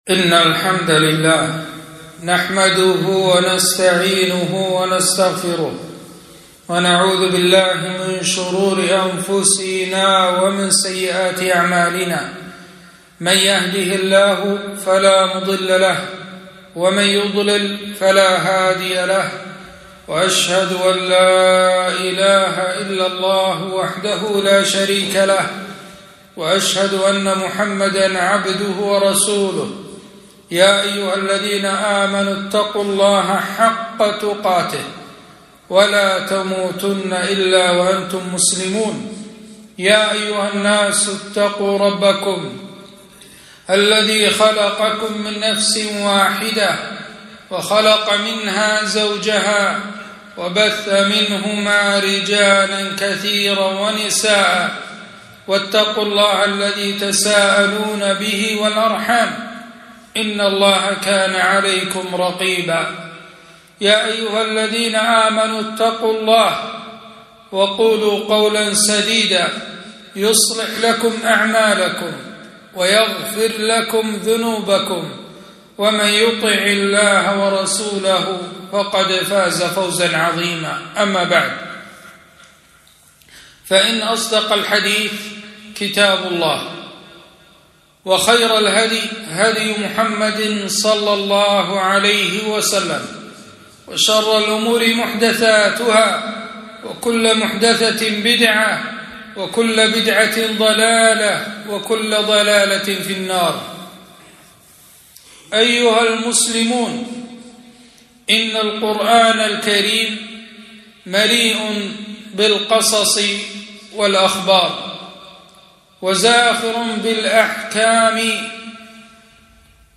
خطبة - فوائد من قصة جرير العابد